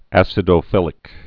(ăsĭ-dō-fĭlĭk) also ac·i·doph·i·lus (-dŏfə-ləs)